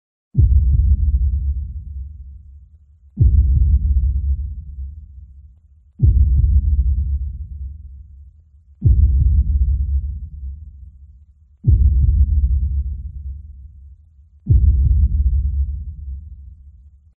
xintiao.mp3